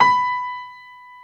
55p-pno30-B4.wav